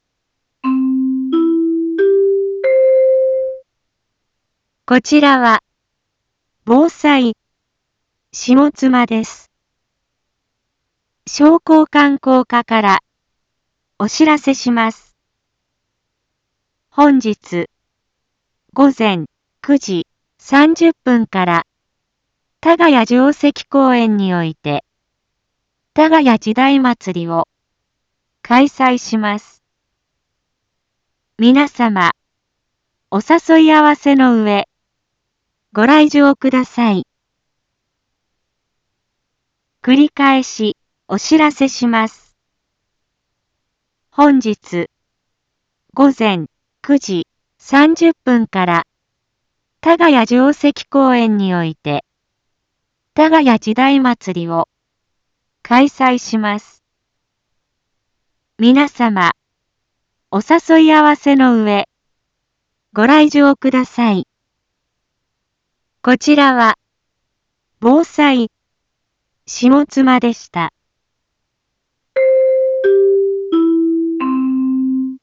一般放送情報
Back Home 一般放送情報 音声放送 再生 一般放送情報 登録日時：2025-04-27 08:31:35 タイトル：多賀谷時代まつり開催のお知らせ インフォメーション：こちらは、ぼうさいしもつまです。